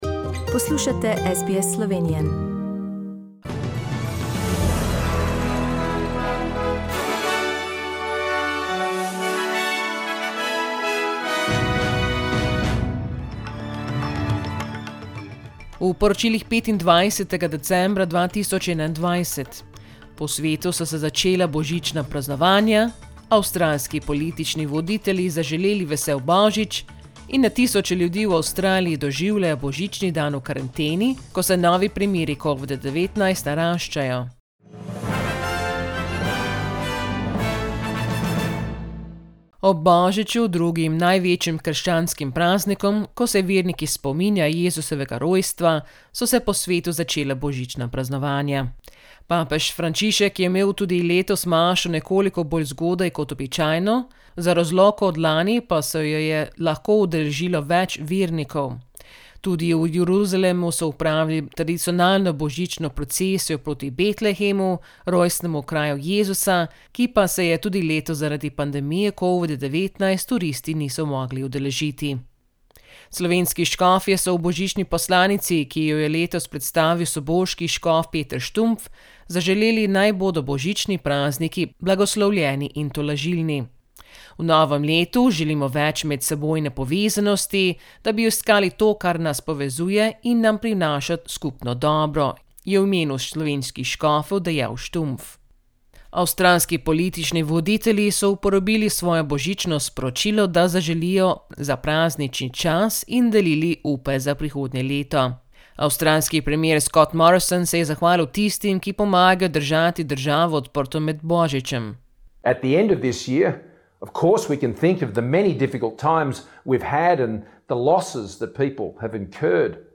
SBS News in Slovenian - 25th December 2021